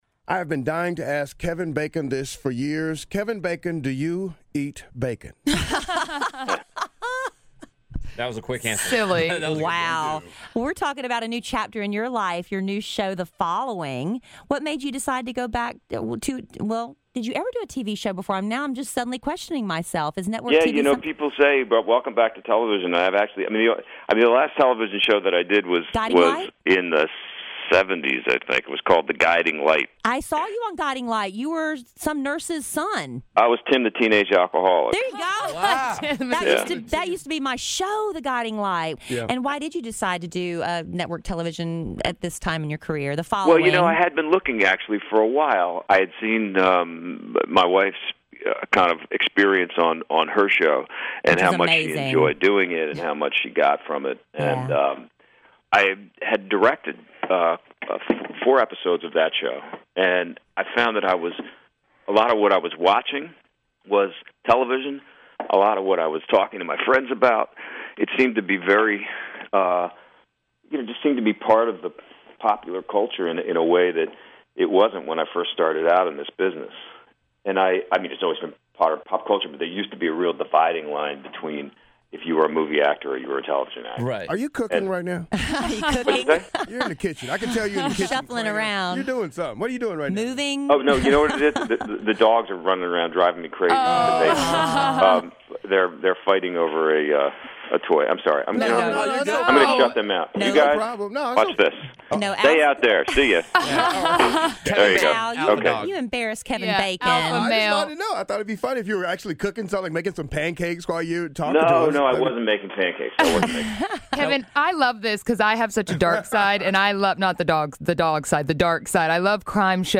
Kevin Bacon Interview
Kidd Kraddick in the Morning interviews Kevin Bacon from The Following.